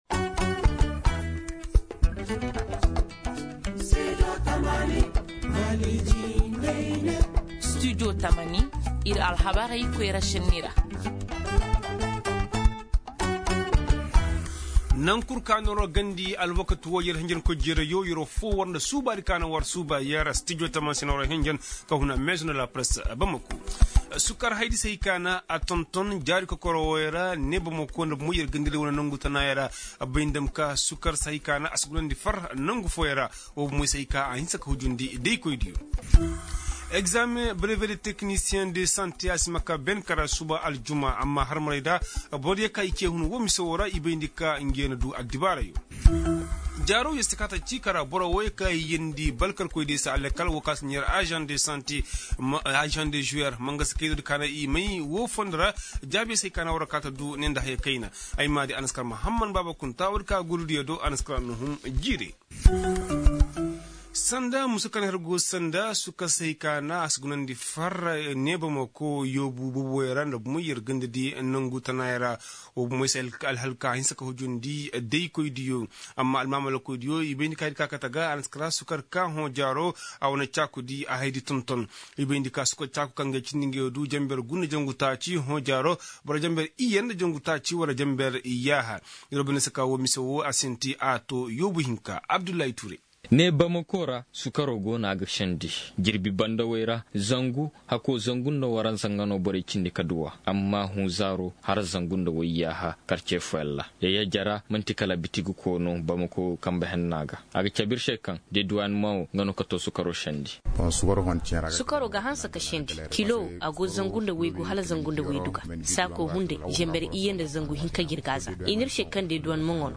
Ci-dessous, écoutez le développement de ces titres dans nos journaux en français et en langues nationales :